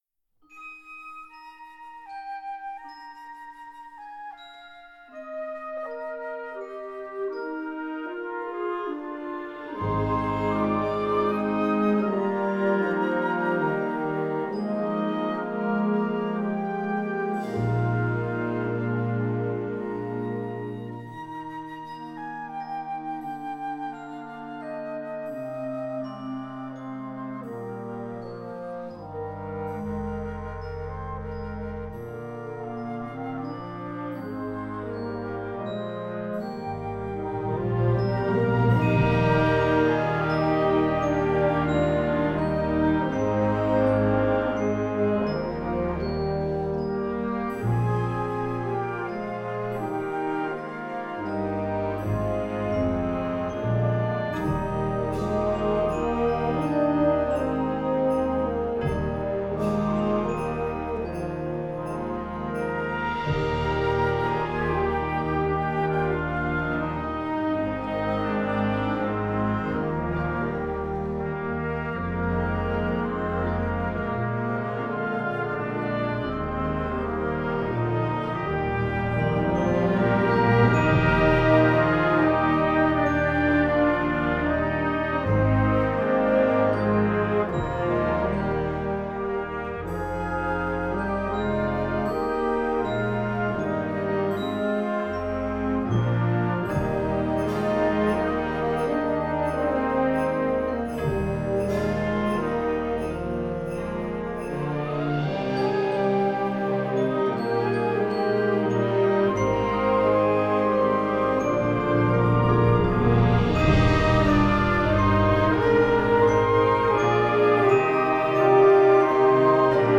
Gattung: Weihnacht für Jugendblasorchester
Besetzung: Blasorchester
einzigartig ausdrucksstarken Arrangement
allmählich auf den Höhepunkt